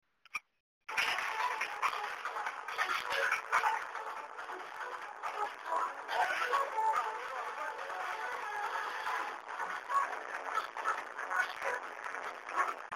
I know this tune, but this sample is too short.